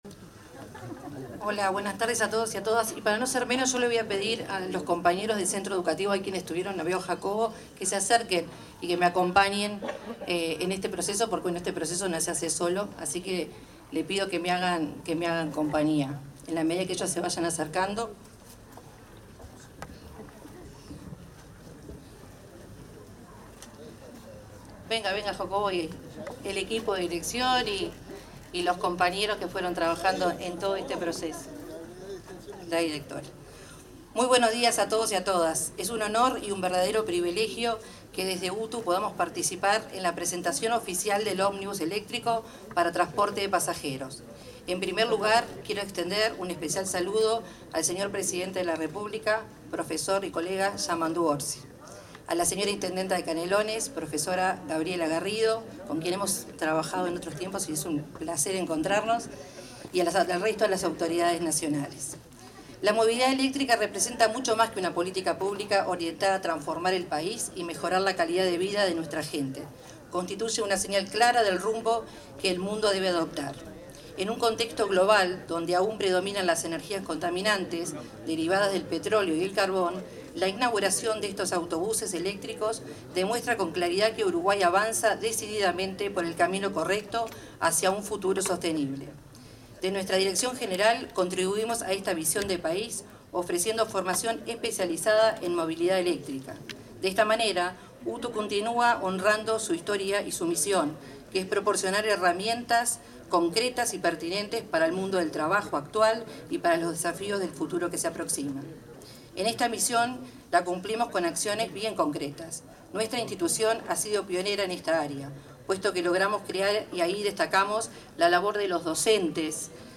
Palabras de la directora general de Educación Técnico Profesional-UTU, Virginia Verderese
Durante la entrega de ómnibus eléctricos en Las Piedras, departamento de Canelones, se expresó la titular de la Dirección General de Educación Técnico